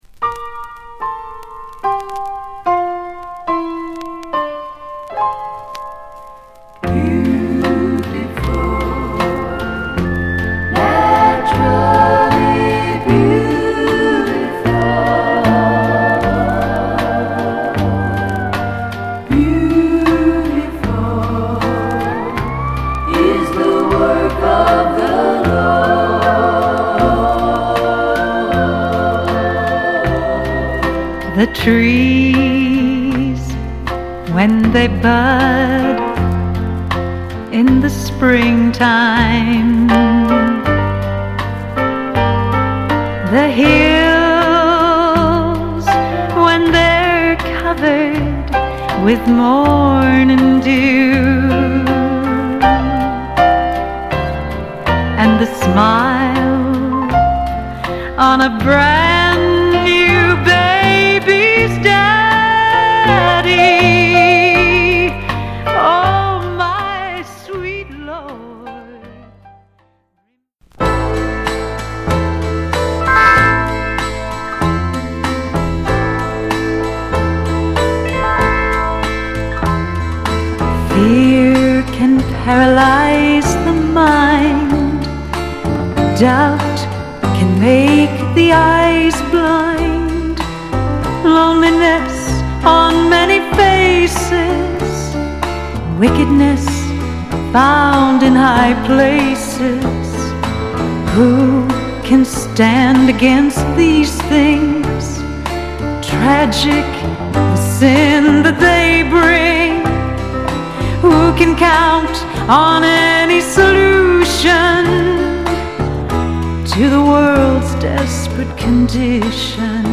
メロウでフォーキーな曲を中心にはつらつとしたカントリーまでを収録。